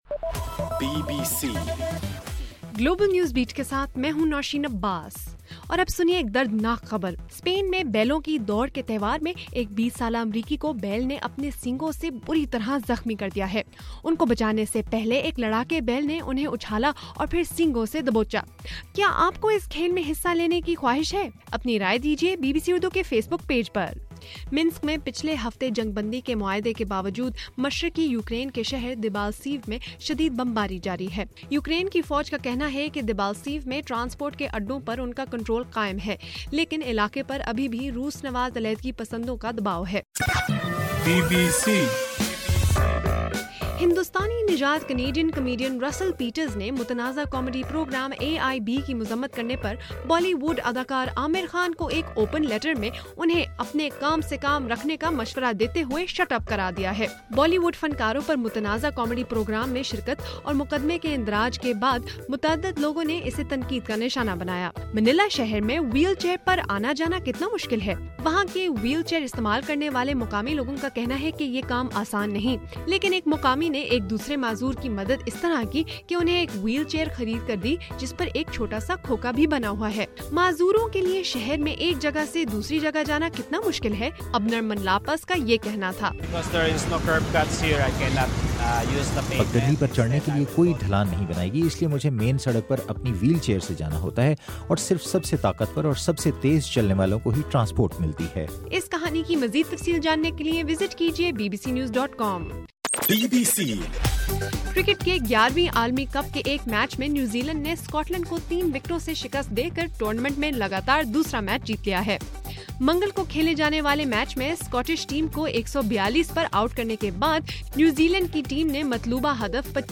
فروری 17: رات 9 بجے کا گلوبل نیوز بیٹ بُلیٹن